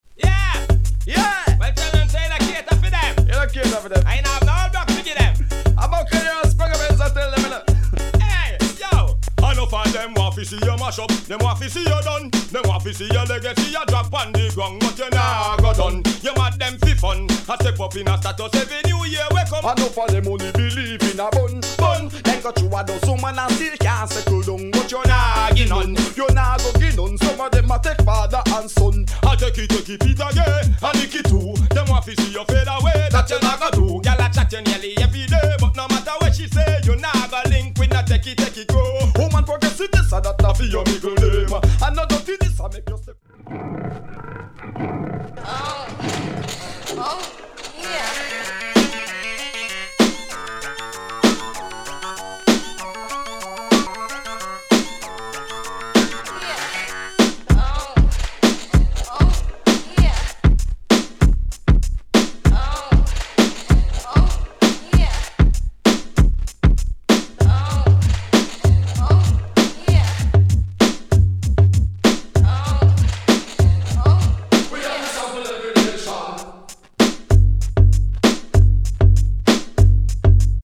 We record our sound files with no EQ is added.